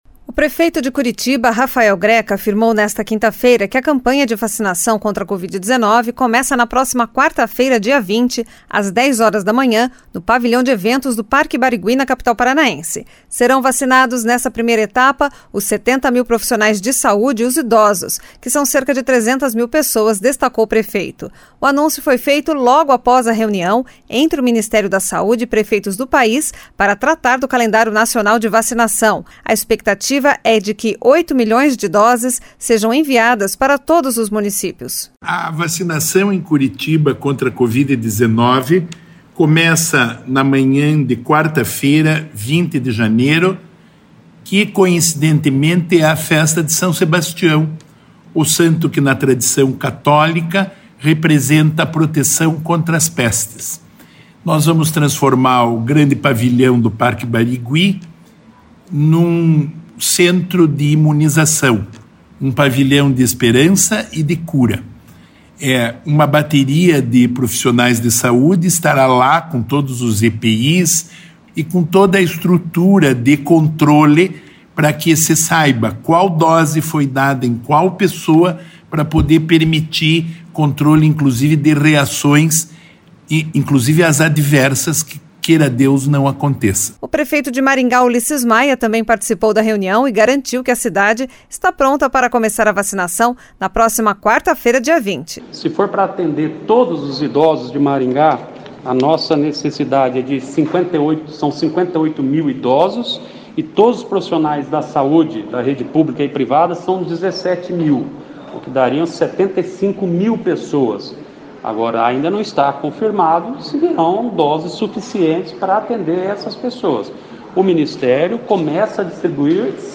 O prefeito de Maringá, Ulisses Maia, também participou da reunião e garantiu que a cidade está pronta para começar a vacinação na próxima quarta-feira.